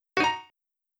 Error1.wav